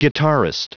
Prononciation du mot guitarist en anglais (fichier audio)
Prononciation du mot : guitarist